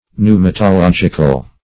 Search Result for " pneumatological" : The Collaborative International Dictionary of English v.0.48: Pneumatological \Pneu`ma*to*log"ic*al\, a. [Cf. F. pneumatologique.]
pneumatological.mp3